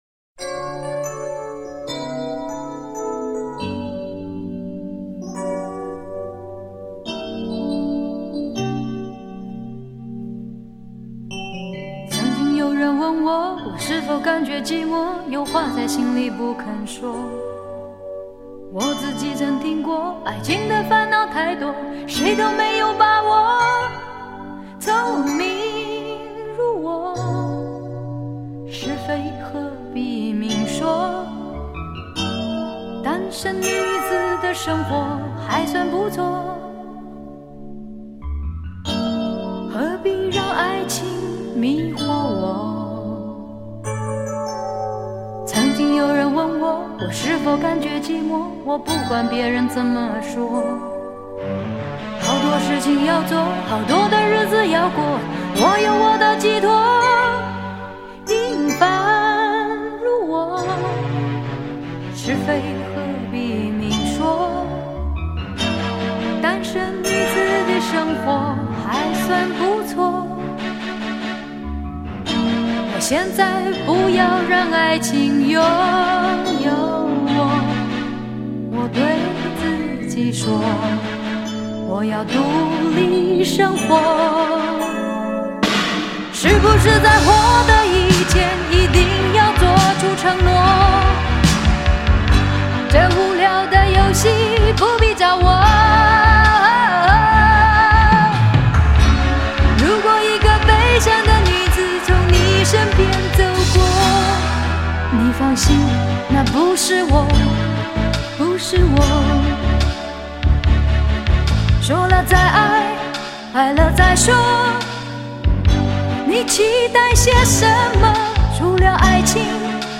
其中收錄多首溫柔婉約的小品式作品。